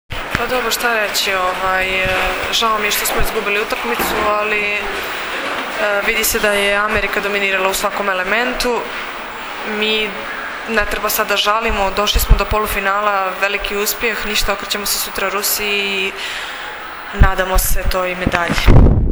IZJAVA SILVIJE POPOVIĆ